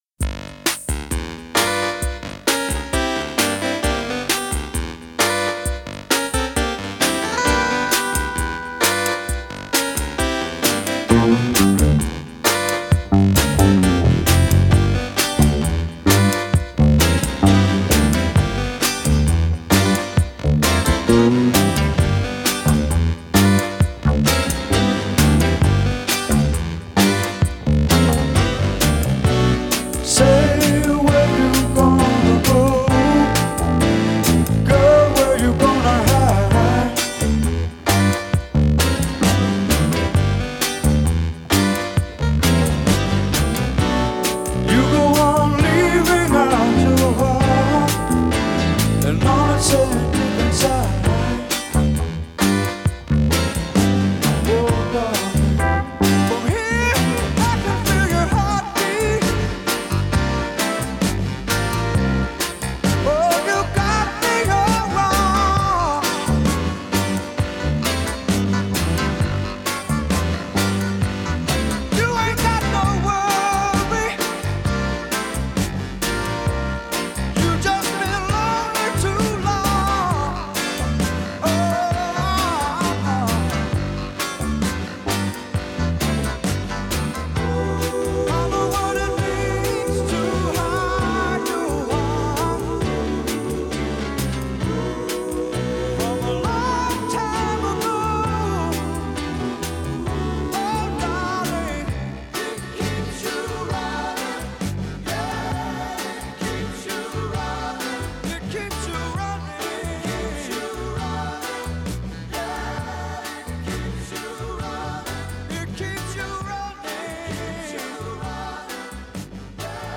soul-infused, keyboard-laced
with its shuffling rhythm
Take it smooth and easy.